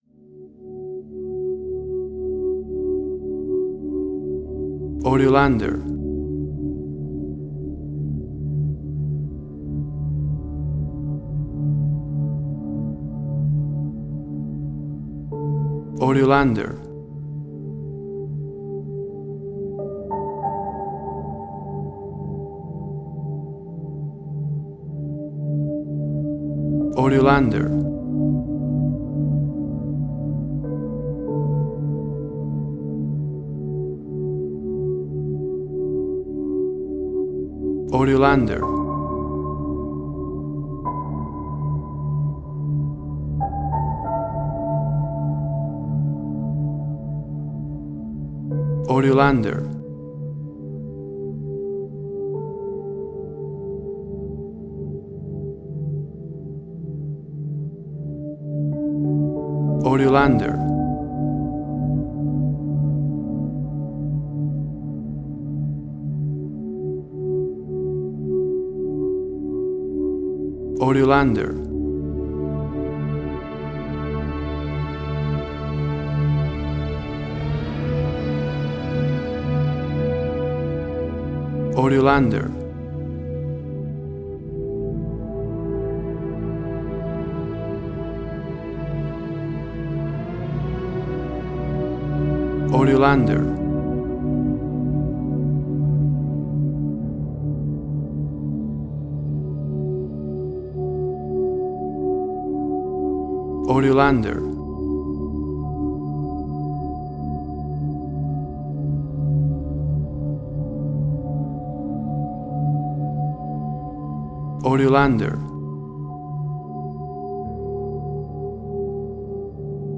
Suspense, Drama, Quirky, Emotional.
WAV Sample Rate: 16-Bit stereo, 44.1 kHz